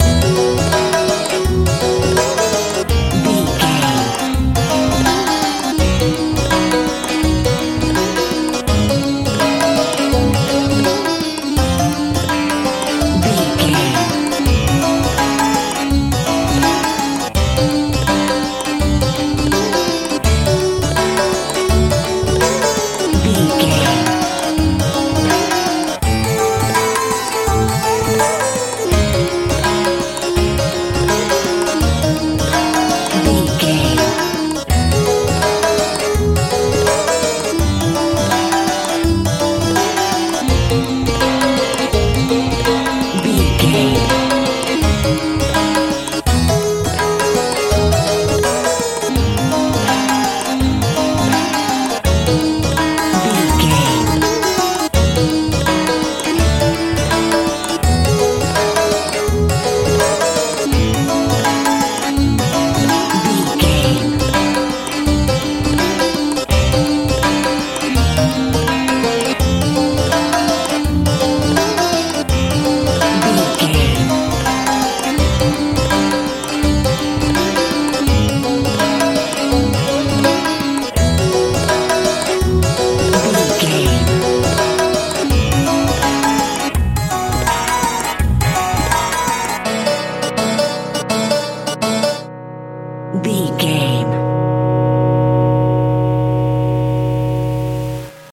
bollywood feel
Ionian/Major
dreamy
relaxed
bass guitar
electric guitar